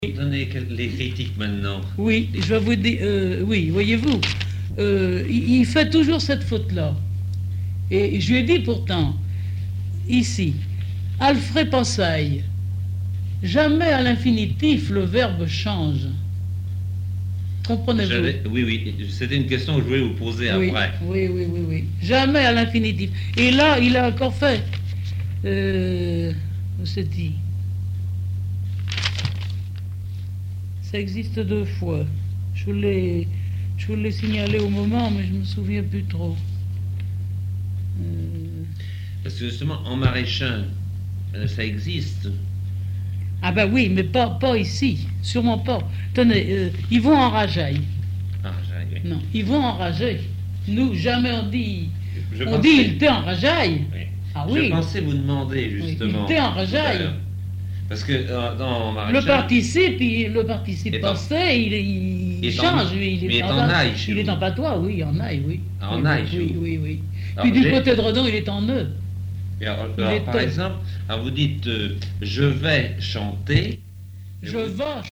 textes en patois et explications sur la prononciation
Catégorie Témoignage